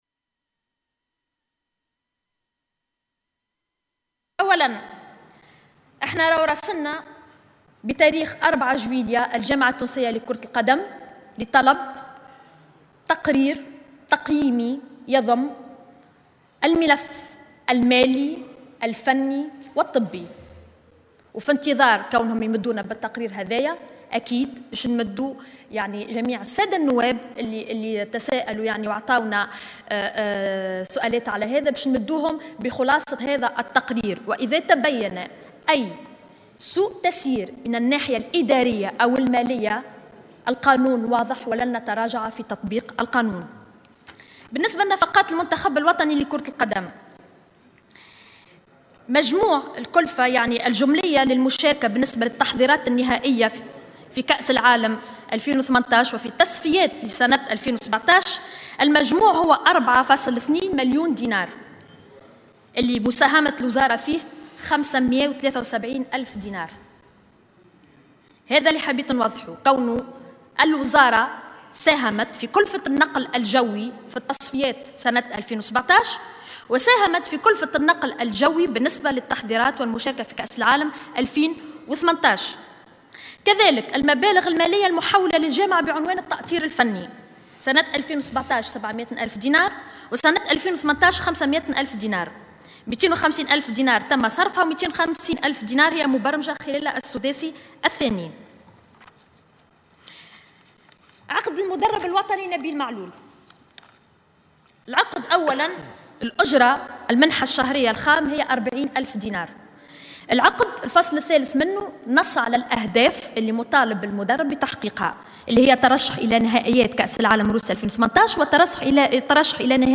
أشارت وزيرة شؤون الشباب و الرياضة ماجدولين الشارني خلال جلسة المساءلة اليوم الجمعة 06 جويلية 2018 أمام مجلس نواب الشعب إلى أن كلفة مشاركة المنتخب الوطني في التصفيات و النهائيات لكأس العالم روسيا 2018 قد بلغت قيمة 4,2 مليون دينار.